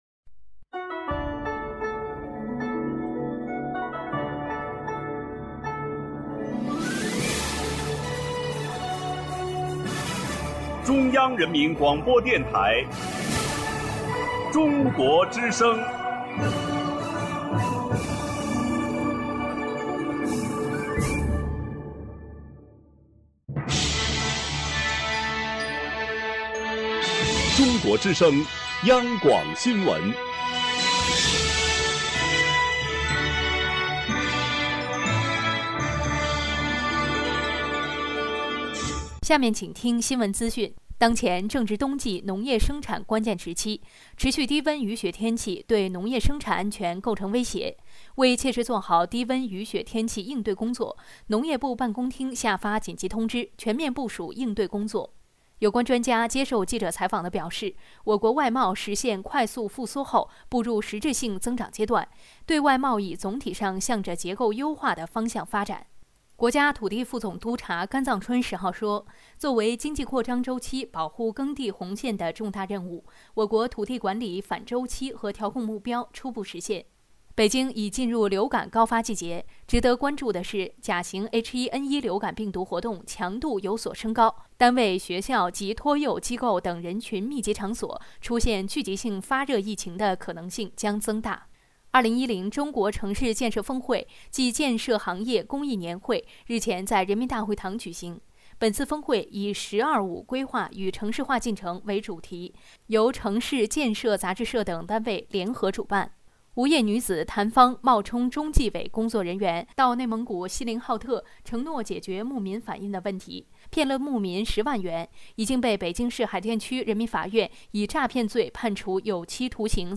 中央电台关于阜新银行CCM消费卡启动仪式的报道录音.mp3